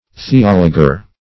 Theologer \The*ol"o*ger\, n.